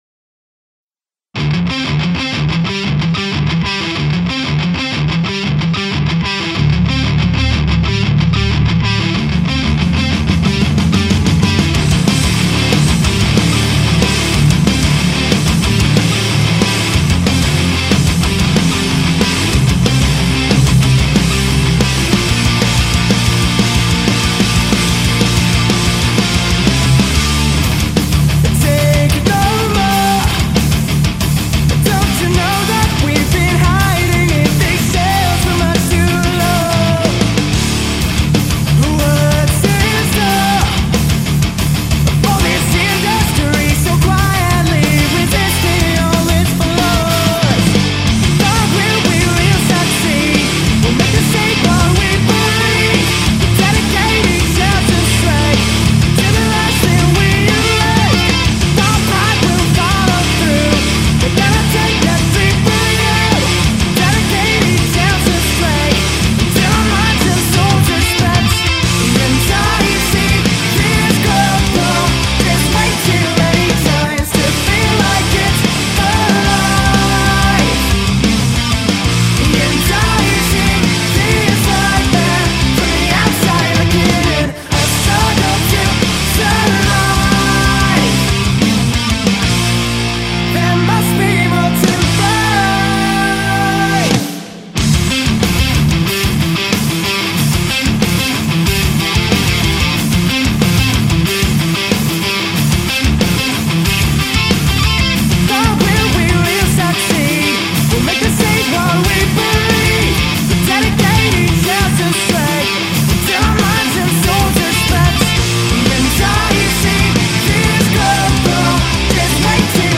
four-piece hard rock band
guitarist
bassist
drummer